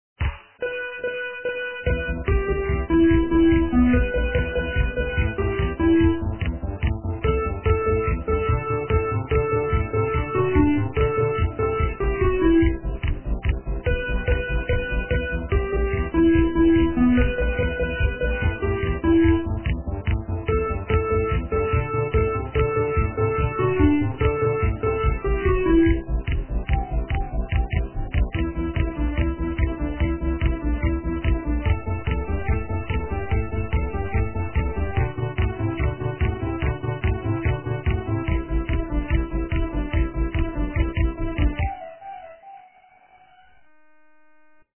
- русская эстрада
качество понижено и присутствуют гудки.
полифоническую мелодию